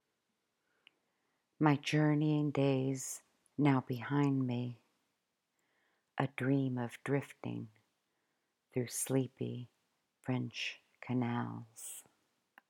To hear me read this tanka, simply click on the link below: